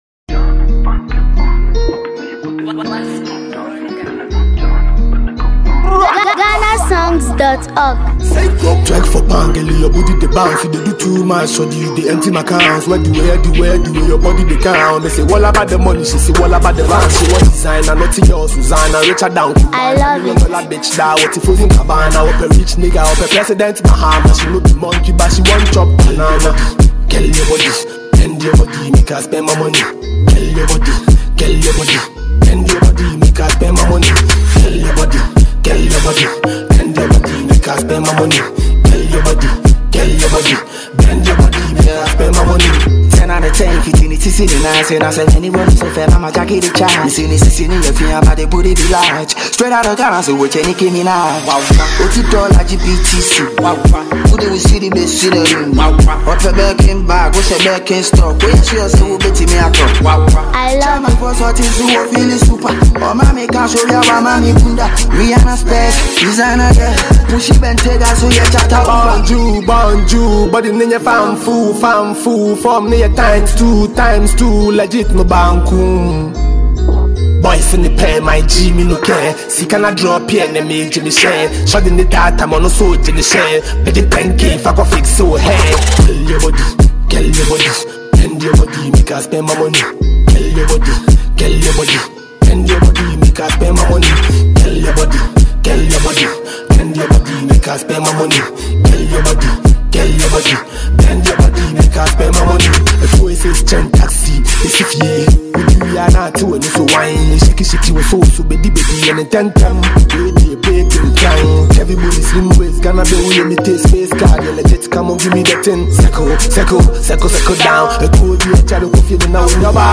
From the infectious beat to the memorable chorus
certified party jam